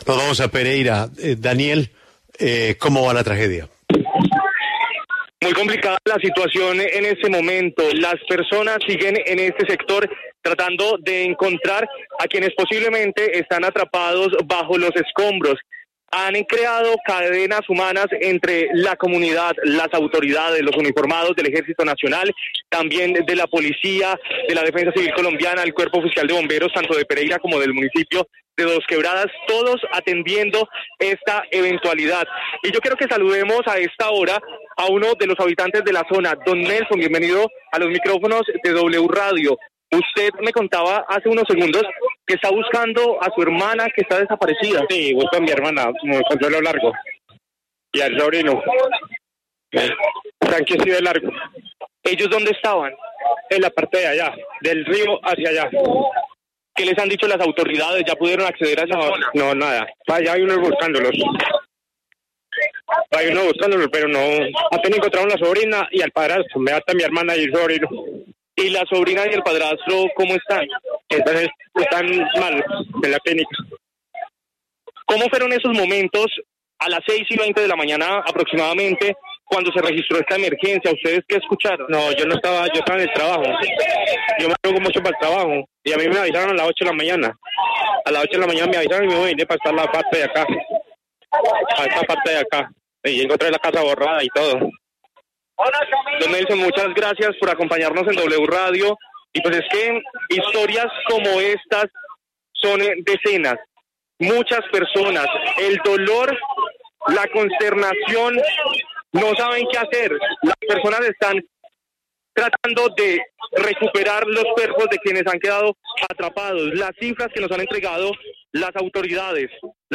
Situación en vivo sobre accidente en Pereira